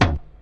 • Old School Reggae Tom Single Hit D# Key 22.wav
Royality free tom sound tuned to the D# note. Loudest frequency: 969Hz
old-school-reggae-tom-single-hit-d-sharp-key-22-z8B.wav